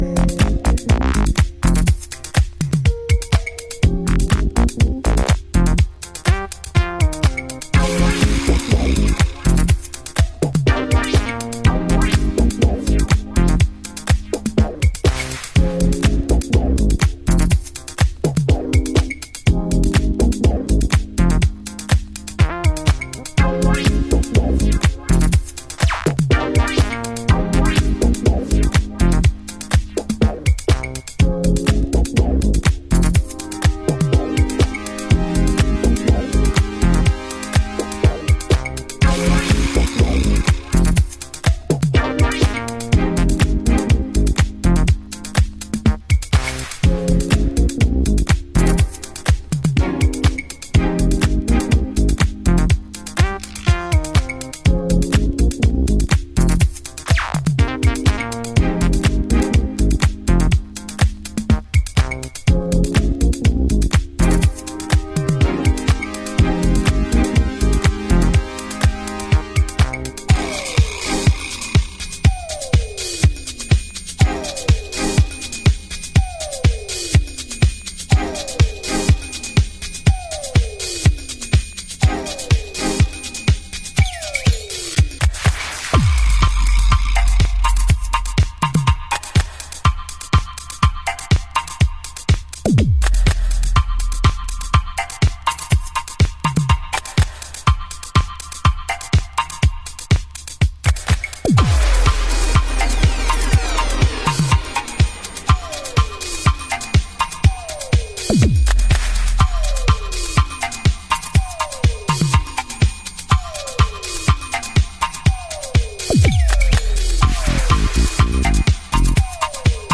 House / Techno
フェティッシュなシンセの揺らぎや、独特のタイム感のブギー・ビートが魅力的。